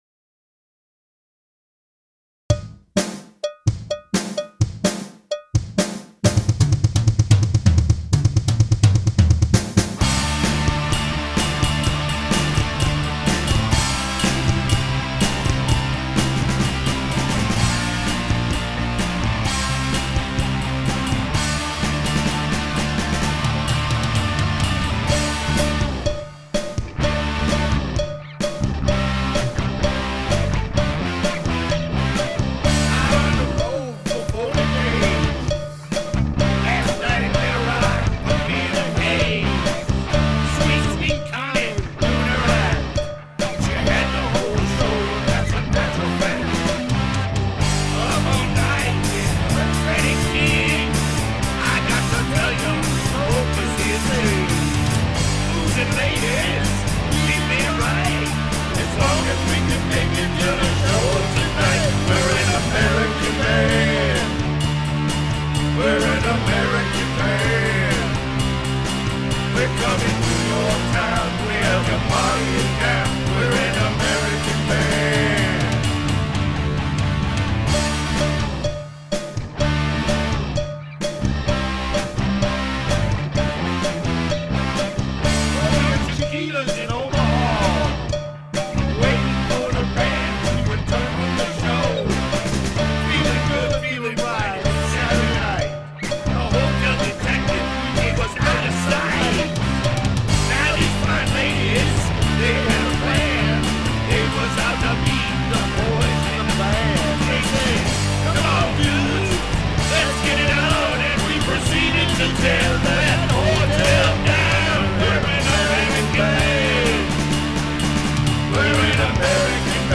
(Special Guest lead guitar
(Special Guest backup singer